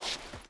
Stone Jump.wav